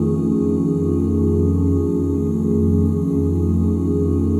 OOHFSHARP9.wav